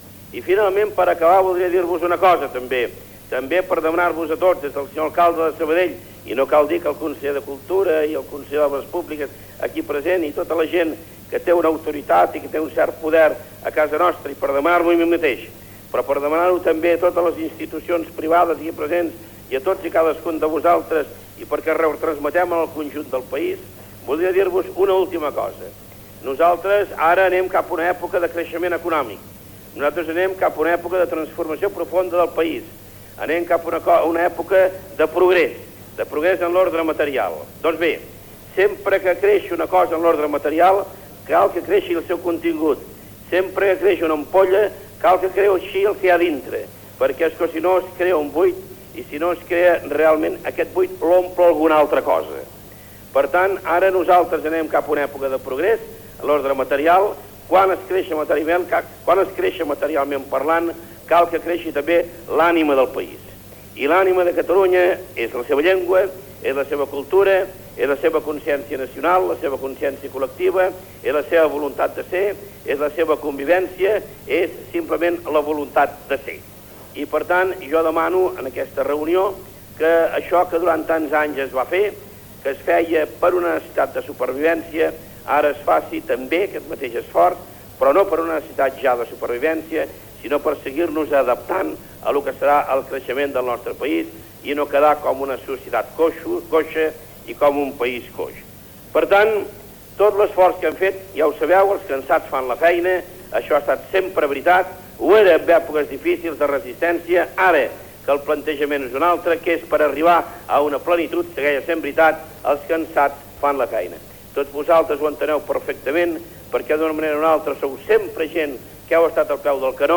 Transmissió de la festa de lliurament de premis de la Nit de Santa Llúcia des de Sabadell.
Final del discurs del president de la Generalitat Jordi Pujol. Entrevista a la locutora Maria Matilde Almendros, que ha rebut un dels premis.
Entrevista a l'escriptor Baltasar Porcel.
Informatiu